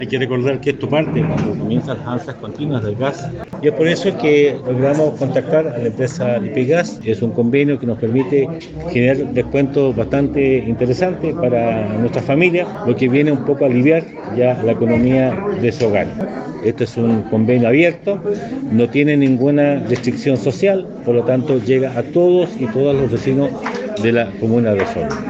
El alcalde de Osorno, Emeterio Carrillo, indicó que este convenio viene a responder en cierta parte el aumento considerable en el precio del gas licuado.